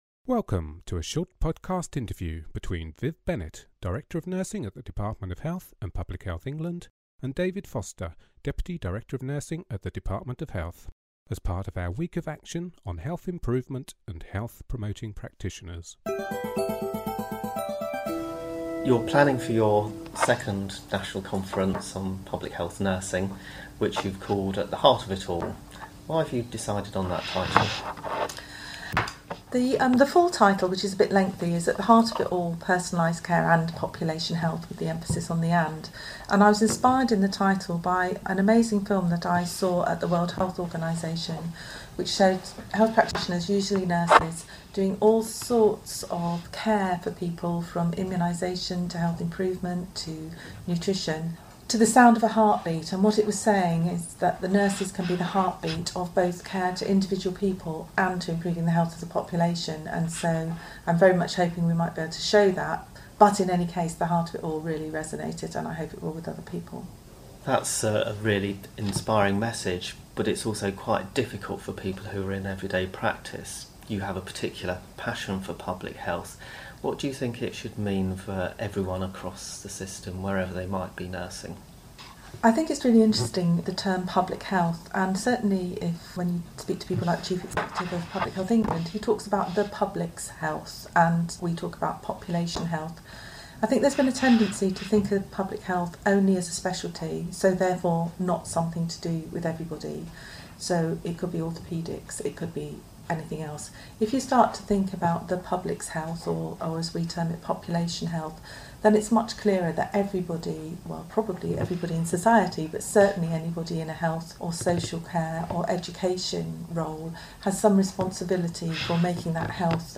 interview on Public Health vision